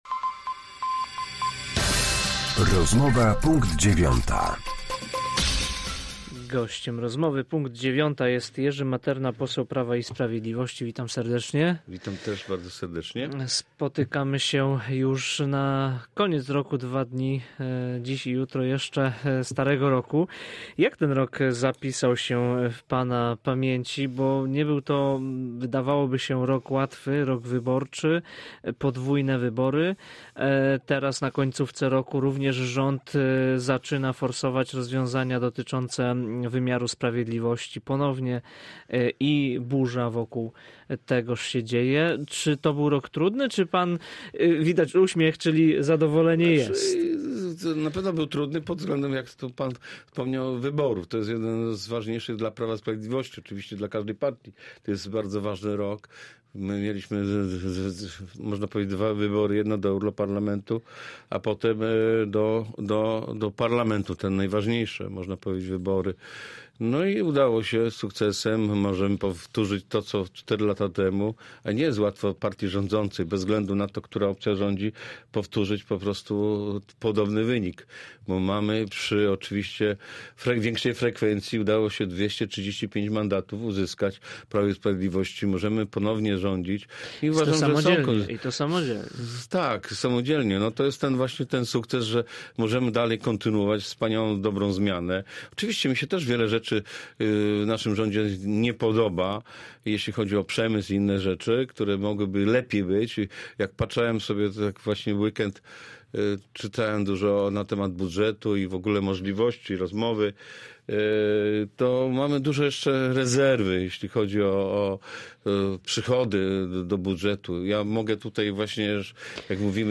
Z posłem Prawa i Sprawiedliwości rozmawiał